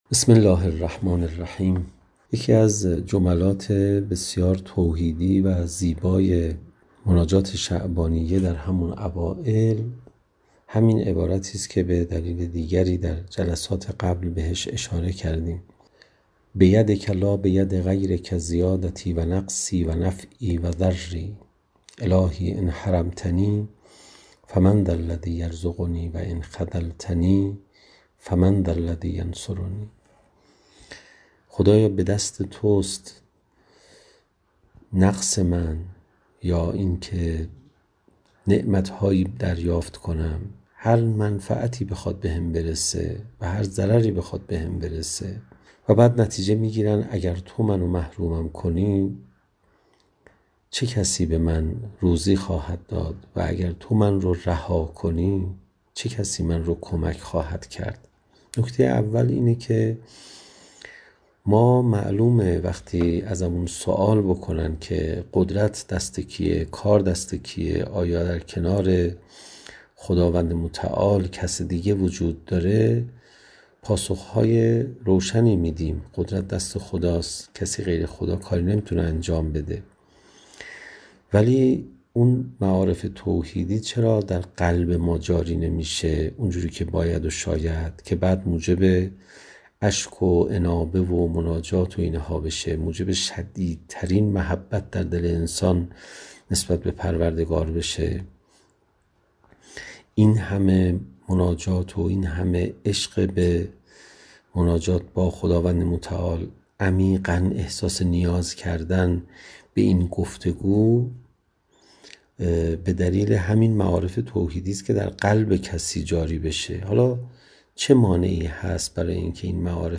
• مکان: جلسه مجازی